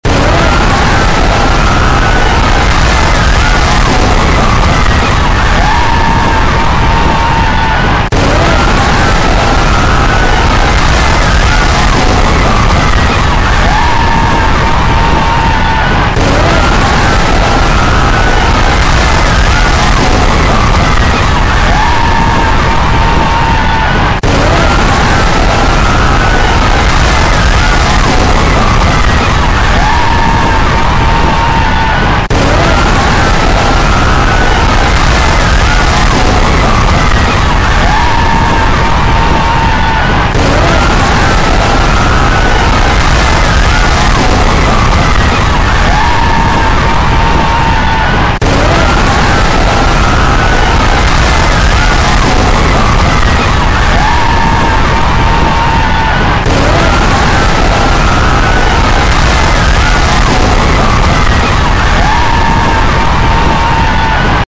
What If a Major Earthquake sound effects free download
The cliff cracks, the falls explode, and tourists run in terror.